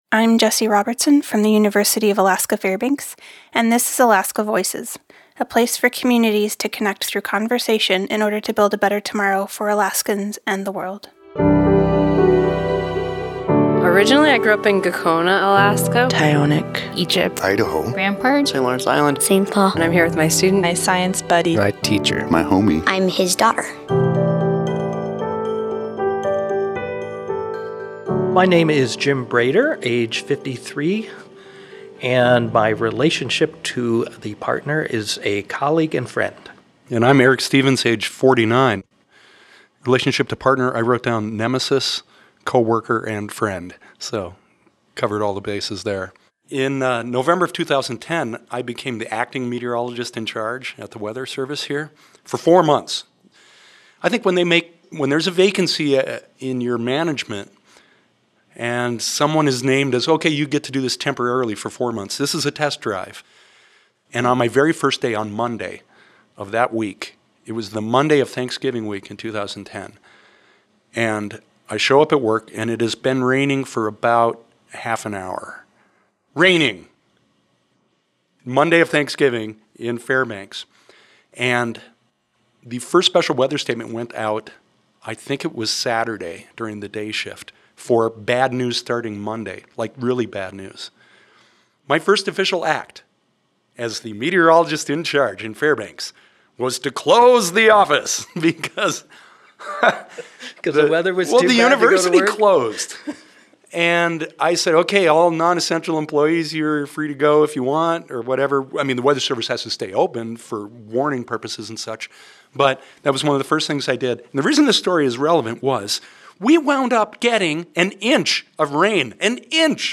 2018 at the University of Alaska Fairbanks in Fairbanks, Alaska.